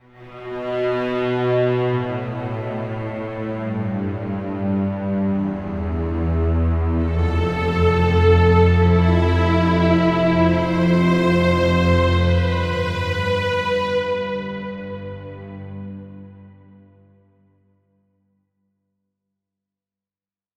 Género: Score.